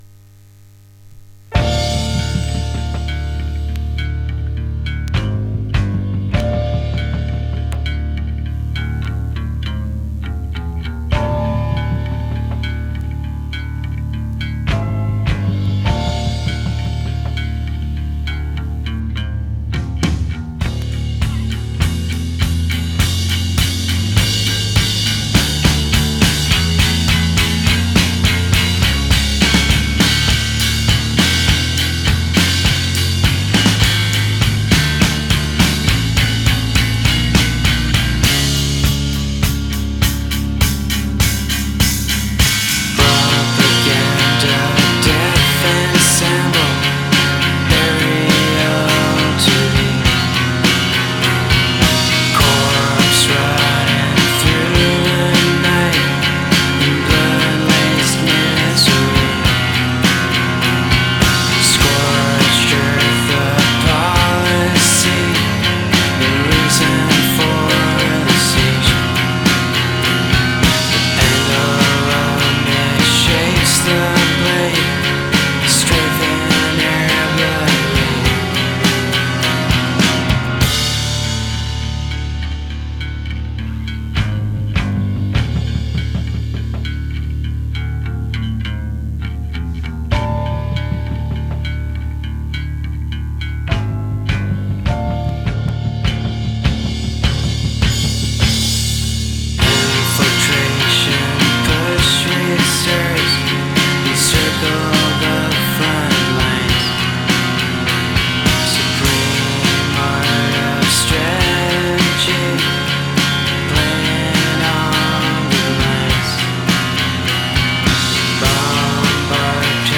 amazing slowcore take